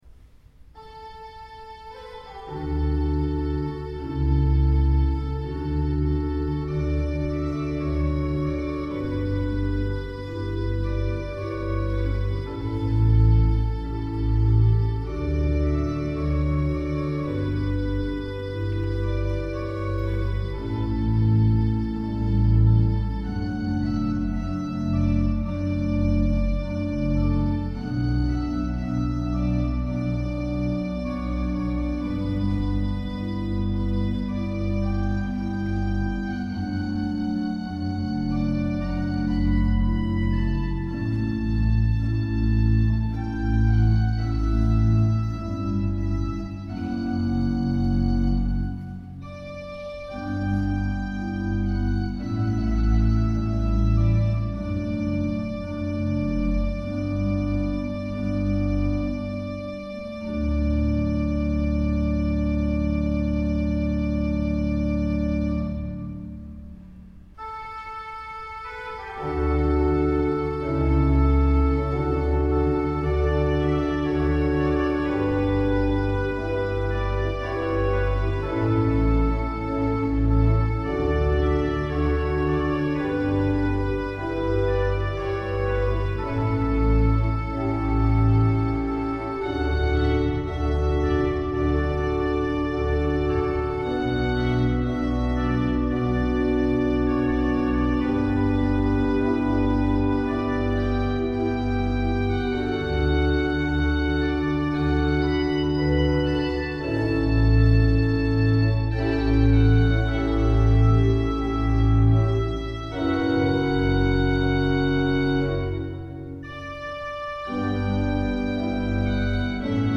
Concierto celebrado en Collbató del 50º aniversario del Órgano del Sol Mayor de Marbella.
Arreglo para órgano